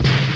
Kick (Blame Game).wav